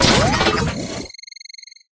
roboturret01.ogg